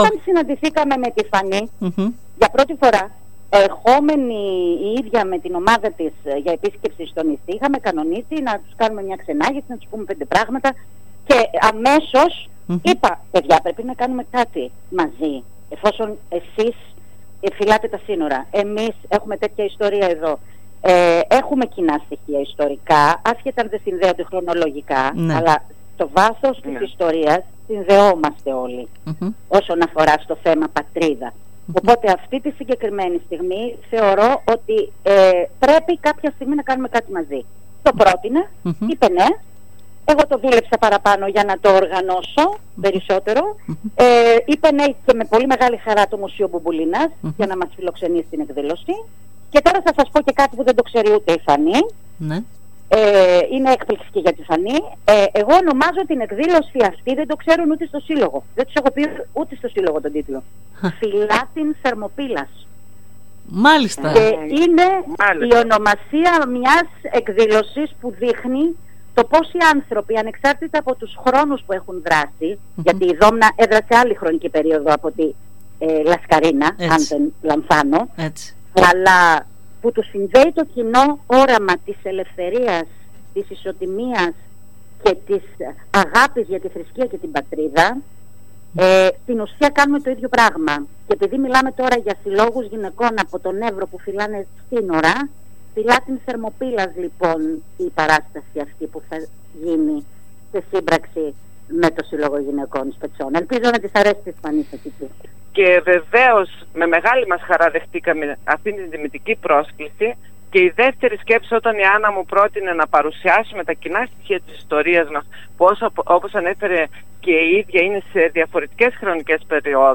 Περιφερειακοί σταθμοί ΟΡΕΣΤΙΑΔΑ